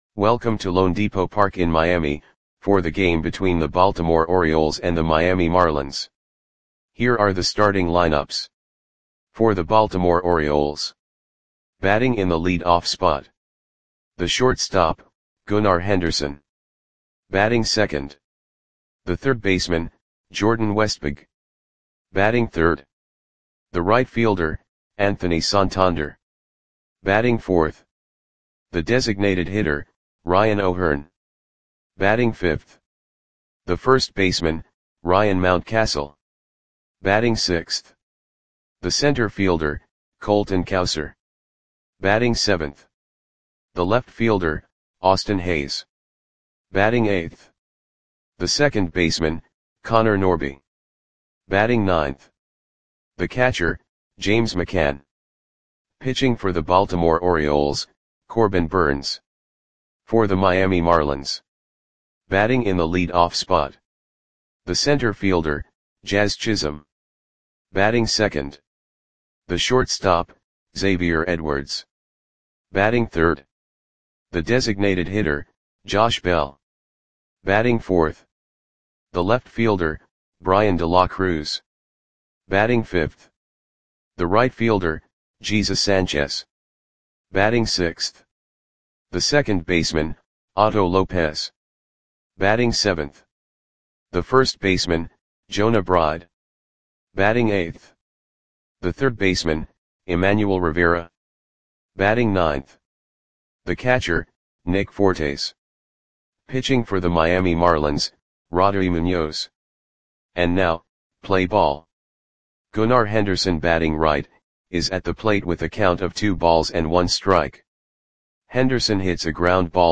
Audio Play-by-Play for Miami Marlins on July 25, 2024